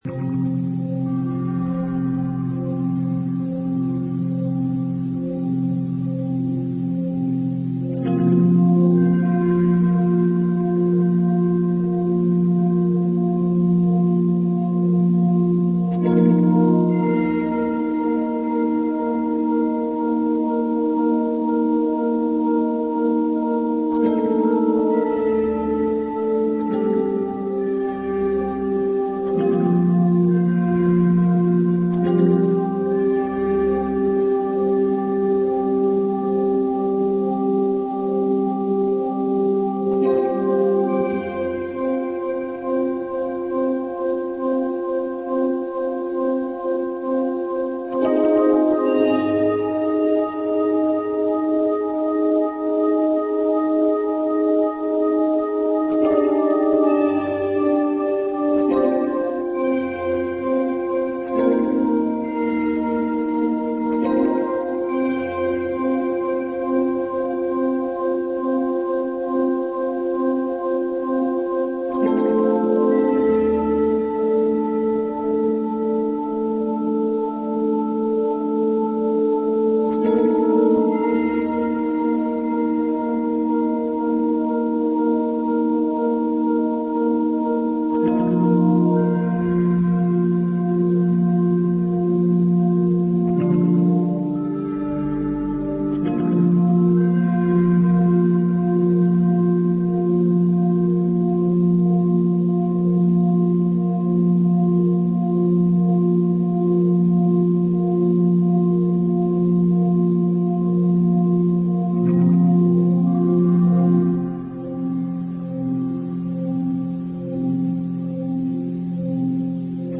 ambient.mp3